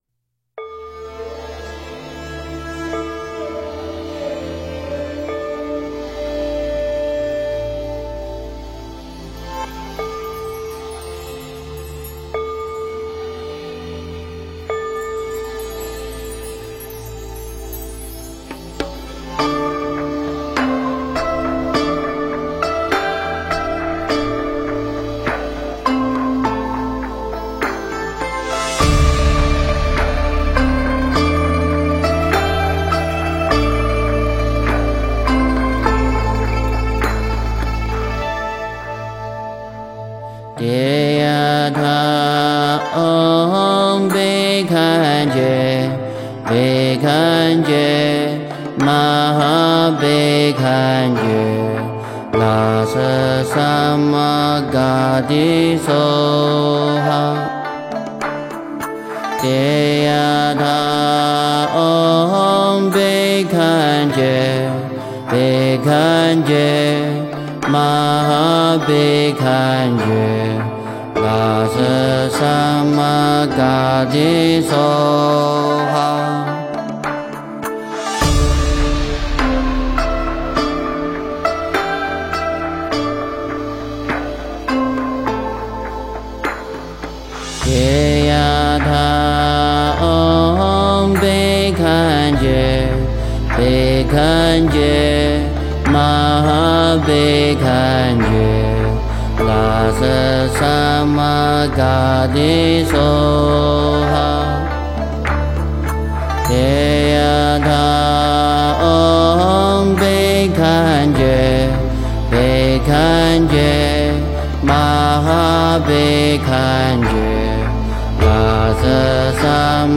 诵经
佛音 诵经 佛教音乐 返回列表 上一篇： 南无地藏菩萨 圣号 下一篇： 渔舟唱晚 相关文章 风雾菩提--巫娜 风雾菩提--巫娜...